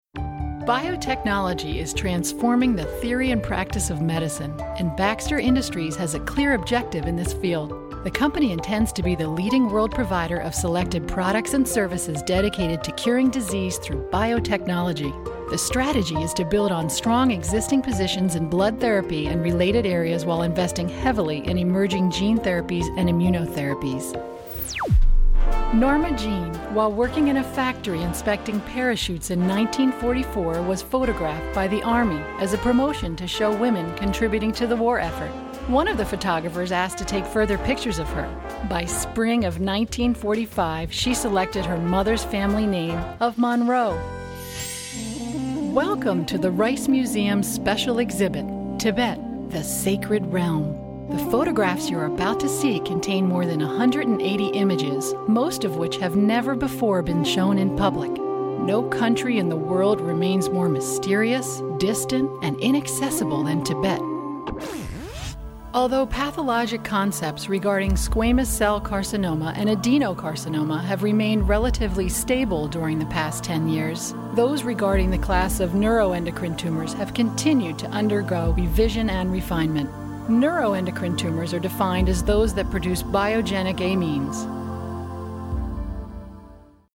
englisch (us)
Kein Dialekt
Sprechprobe: Industrie (Muttersprache):
Experienced full time VO artist with private studio.